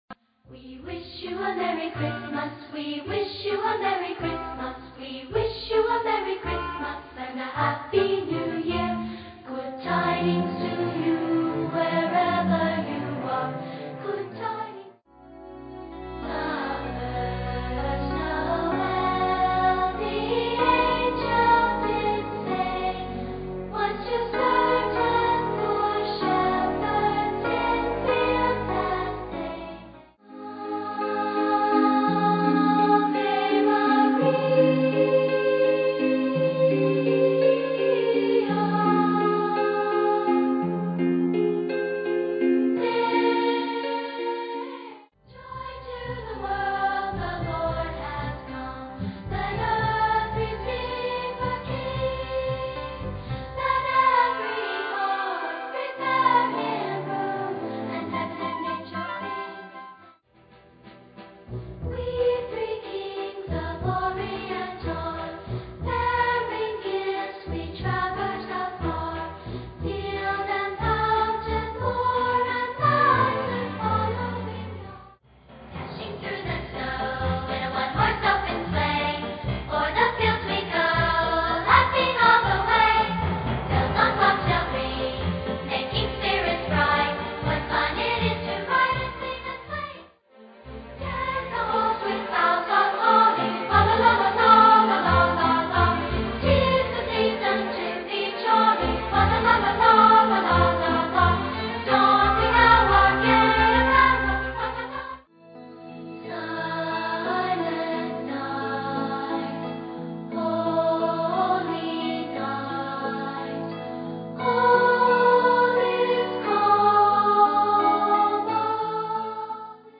Vocal and instrumental
Traditional